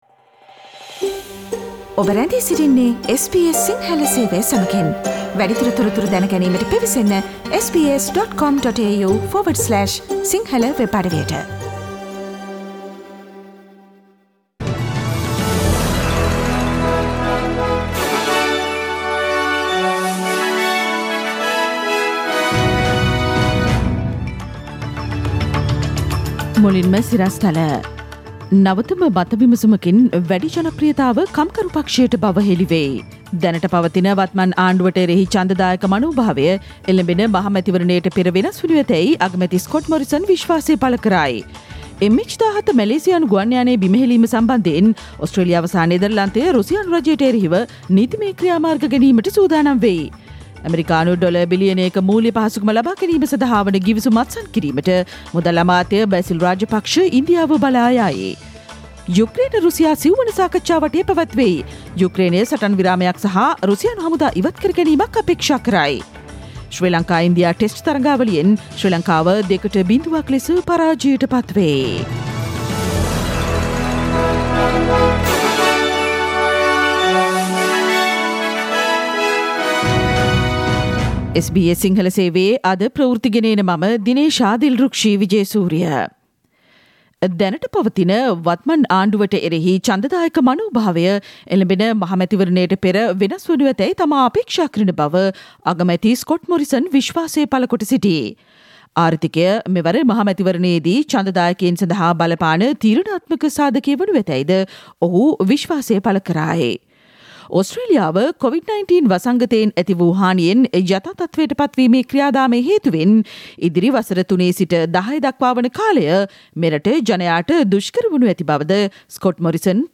Click on the speaker icon on the image above to listen to the SBS Sinhala Radio news bulletin on Tuesday 15 March 2022.